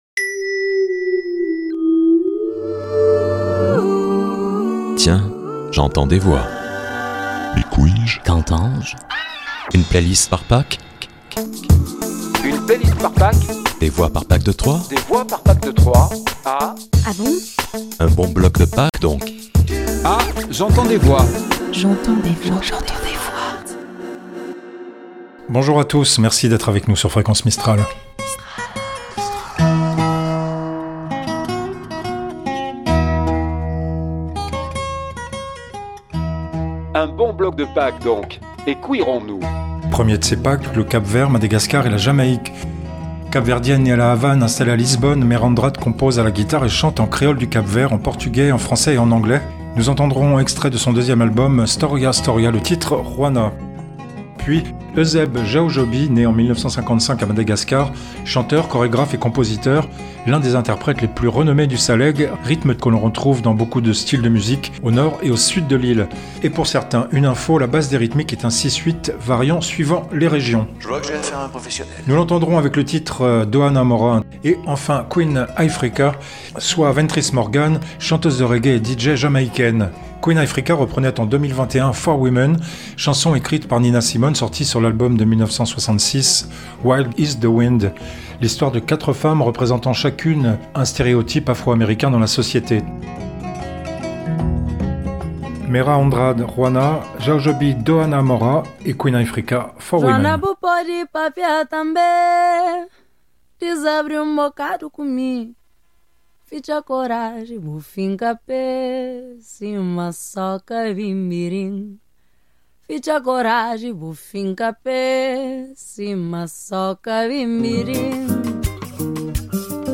PACKBLUES
PACKFUNK
Générique & jingles, voix additionnelles